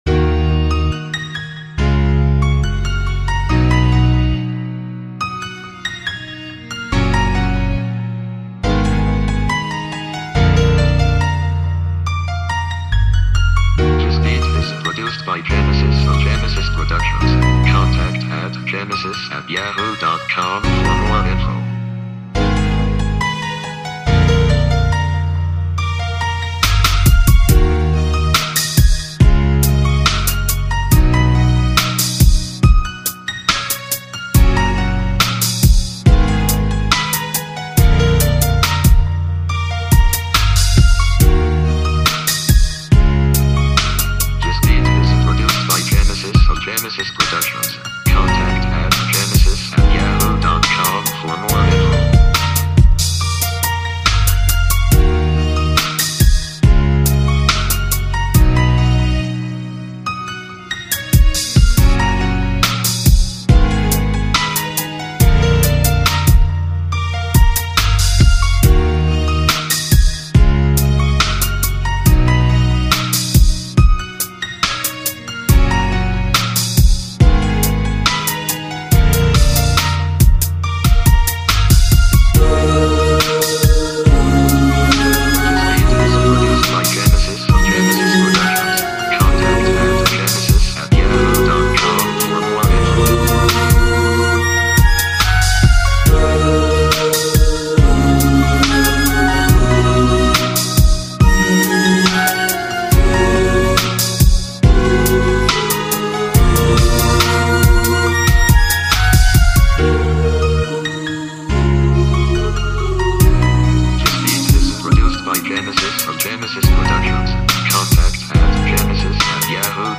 R&B Instrumentals